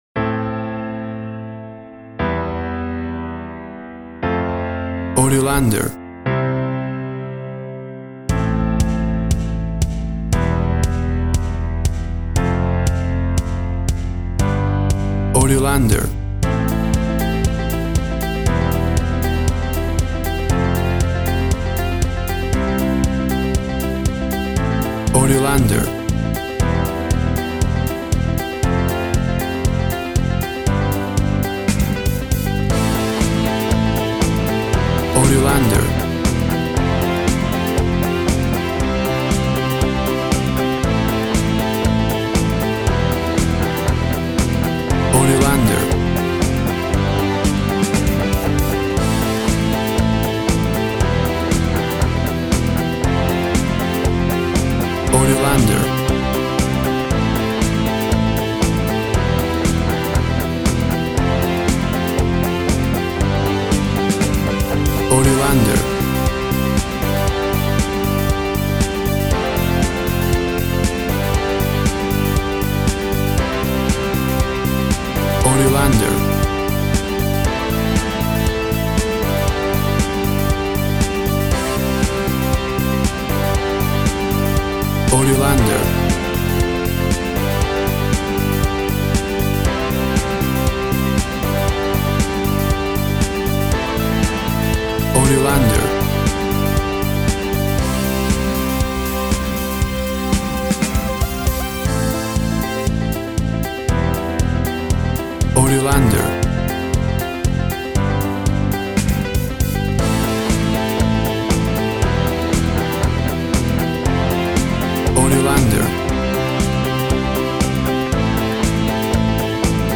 WAV Sample Rate 16-Bit Stereo, 44.1 kHz
Tempo (BPM) 118